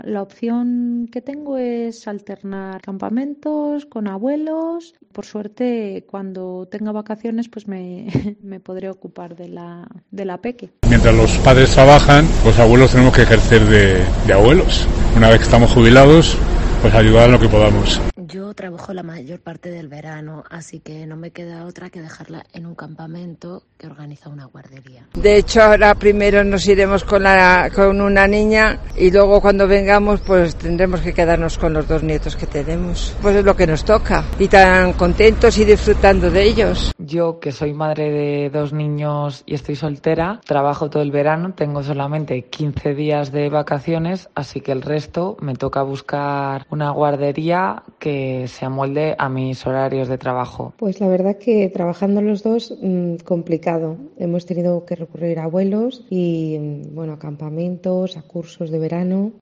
Tren de voces padres y abuelos.